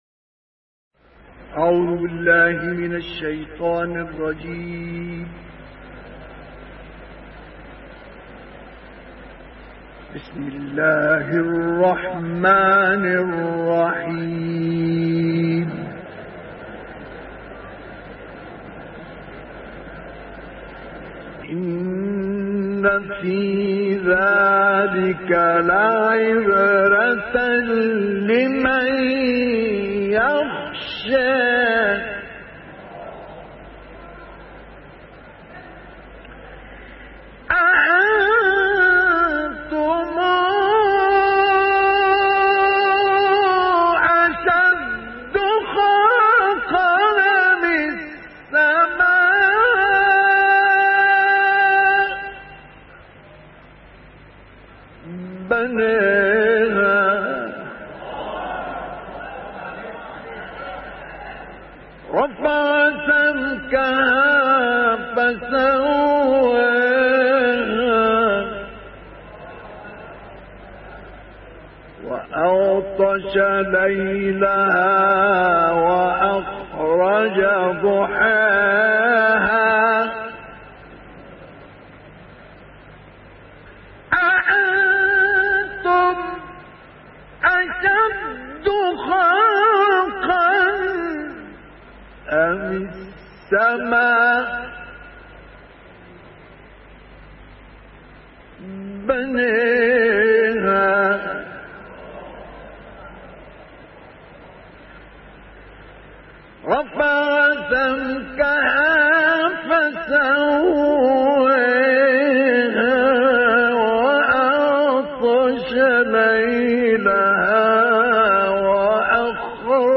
تلاوت کوتاه مجلسی
تلاوت کوتاه مجلسی مصطفی اسماعیل از آیه 26 تا 33 سوره نازعات به مدت 5 دقیقه